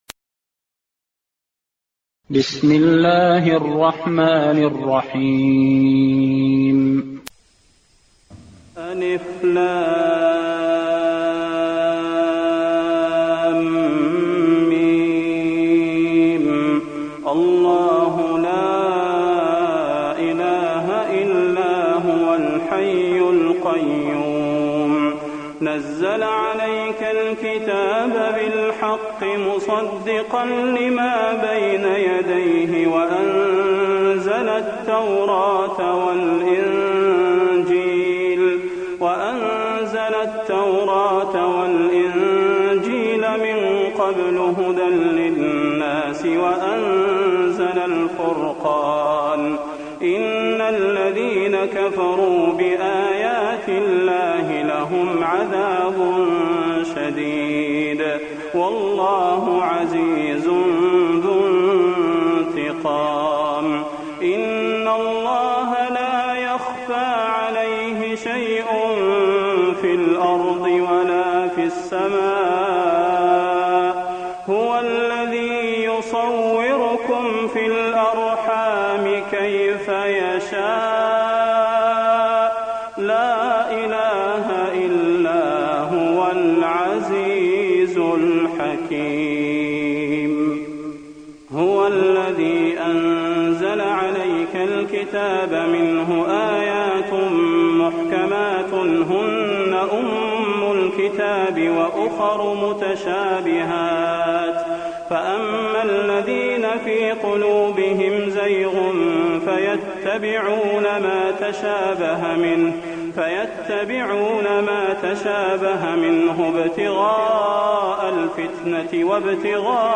تراويح الليلة الثالثة رمضان 1423هـ من سورة آل عمران (1-51) Taraweeh 3st night Ramadan 1423H from Surah Aal-i-Imraan > تراويح الحرم النبوي عام 1423 🕌 > التراويح - تلاوات الحرمين